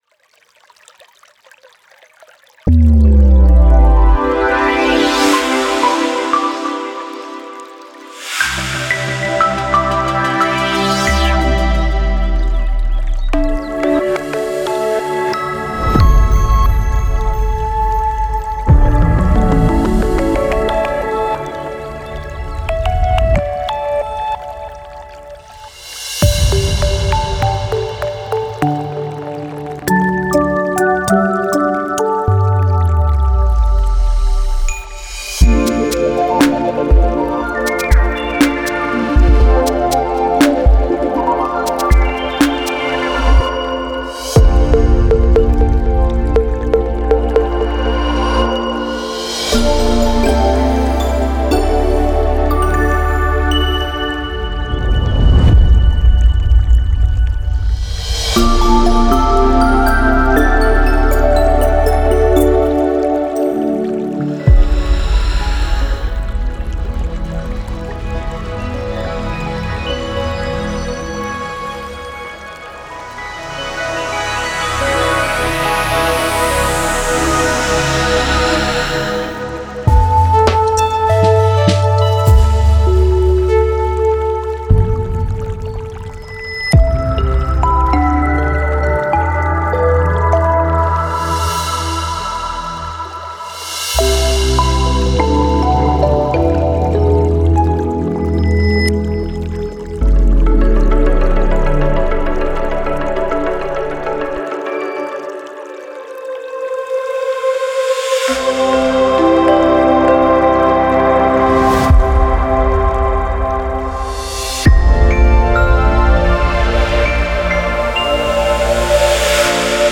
音を通じて上品さ、落ち着き、自信を表現するよう専門的に設計されています。
温かみのあるエーテリアルなスウィッシュ、優雅なストリングフレーズ、ピアノのアクセント、共鳴するチャイム、豊かなシンセパッドを中心に構築され、有機的なディテールと現代的な制作美学を融合させています。
各サウンドは高忠実度で滑らかに鳴り、洗練されたタイムレスなスタイルを感じさせます。
Genre:Cinematic